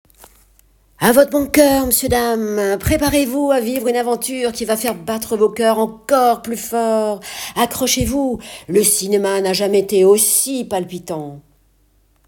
Pub cinéma !
49 - 60 ans - Mezzo-soprano